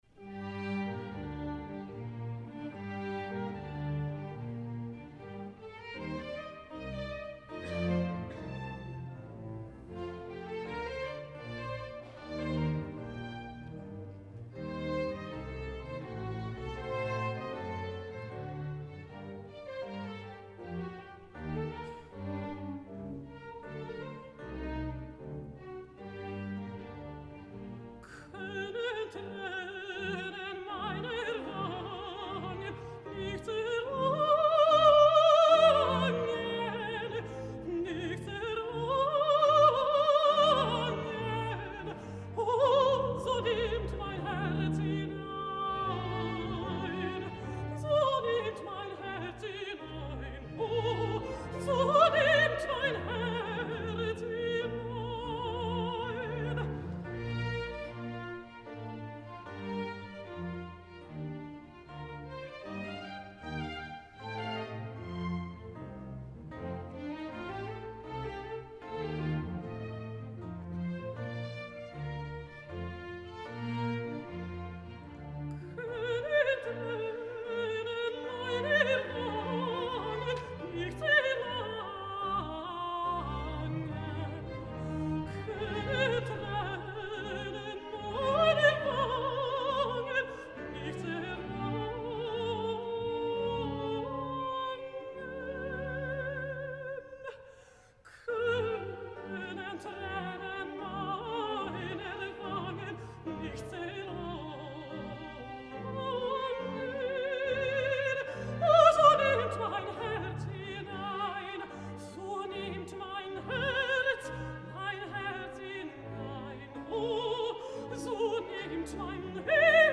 Symphony Hall, Birmingham
mezzo-soprano